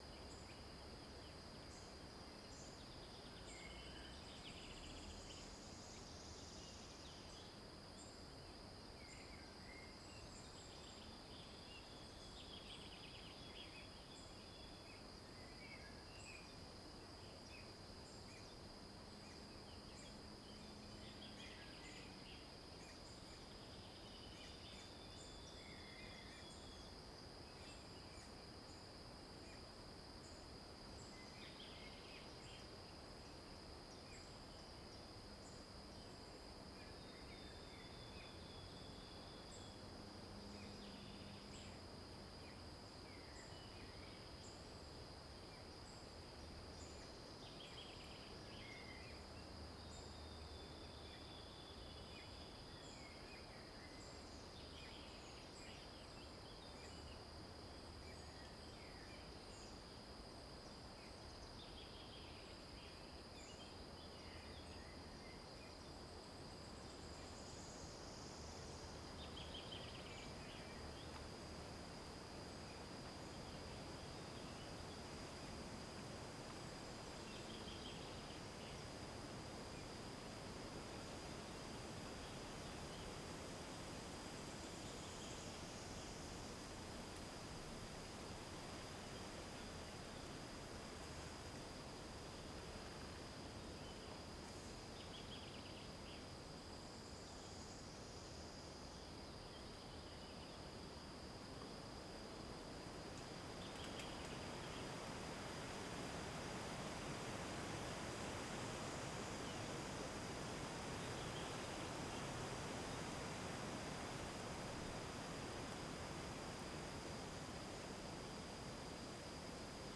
forest_ambix.wav